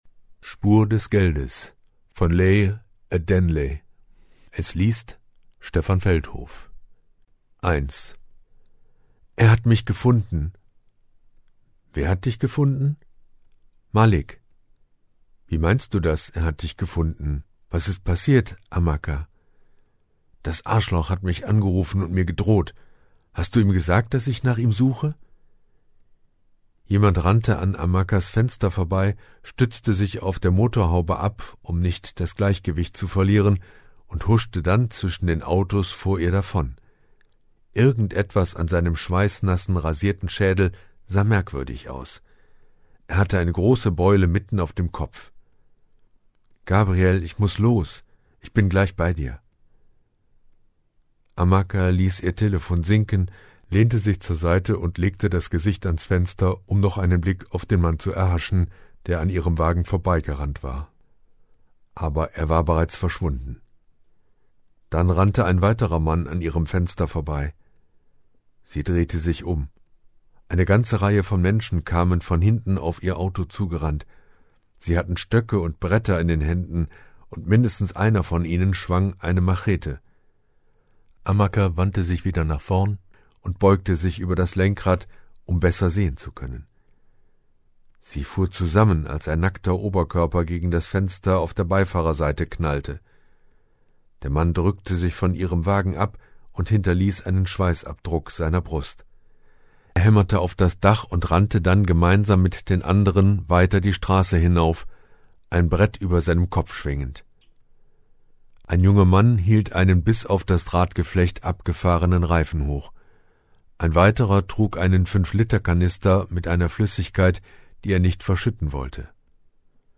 liest diesen Teil der spannenden Thriller-Reihe: